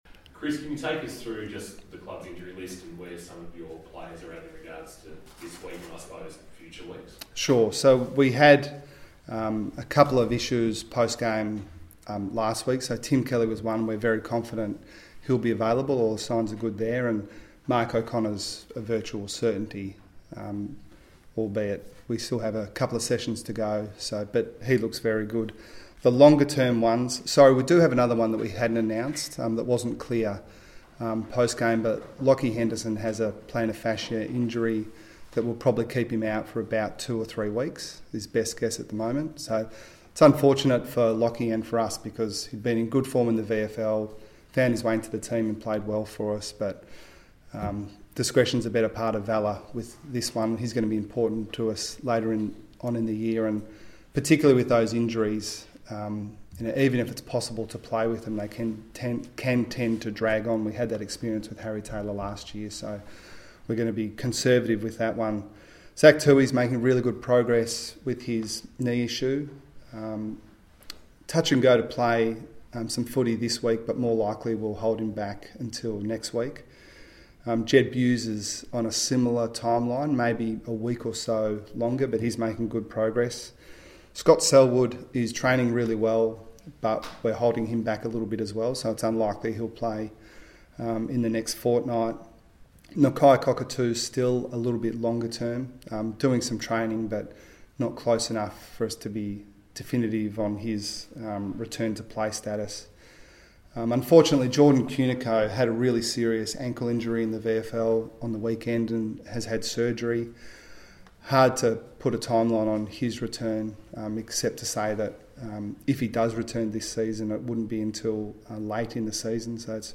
Geelong coach Chris Scott faced the media ahead of Saturday's clash with the GWS Giants.